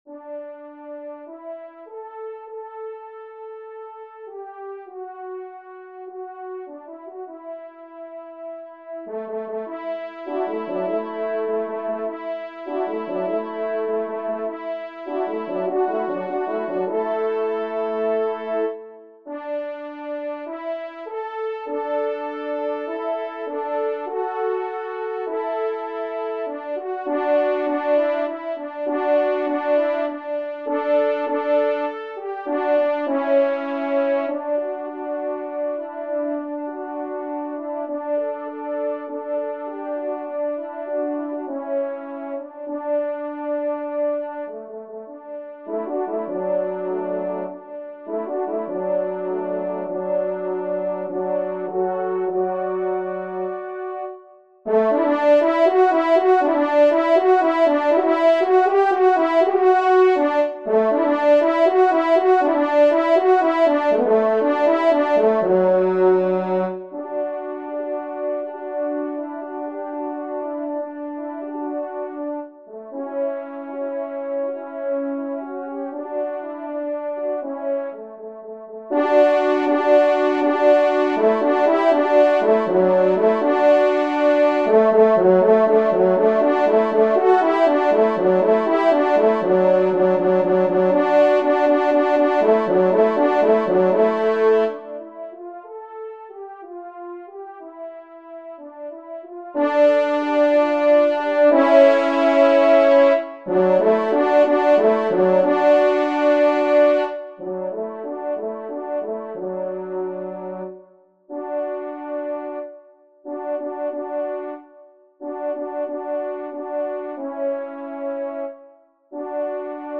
Genre :  Divertissement pour Trompes ou Cors & Orgue
Pupitre 2°Trompe